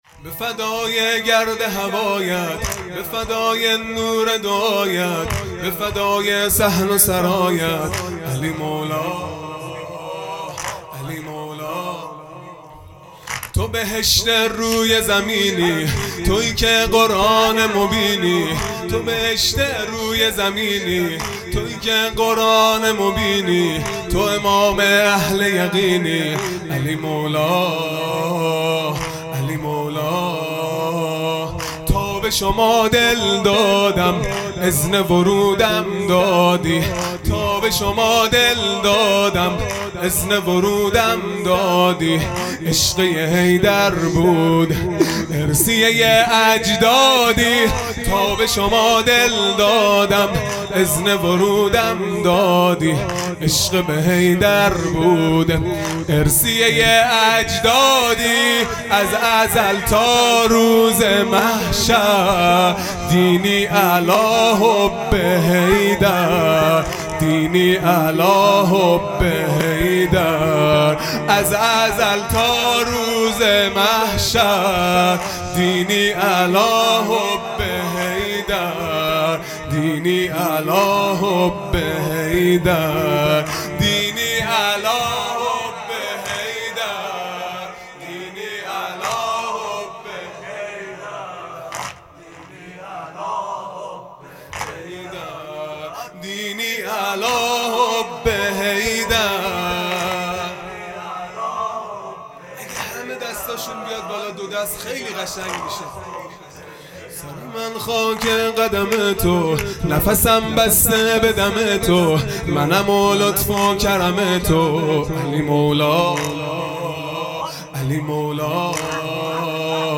خیمه گاه - هیئت بچه های فاطمه (س) - واحد | به فدای گرد هوایت